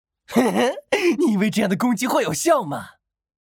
Game VO
His voice mirrors the dramatic contrasts in his life—often cynical and nonchalant in daily conversations, yet transforming into a passionate embodiment of justice at critical moments.
A hoarse, gritty tone paired with a signature unsettling laugh creates striking contrast—outwardly cold and ruthless, yet inwardly yearning for light.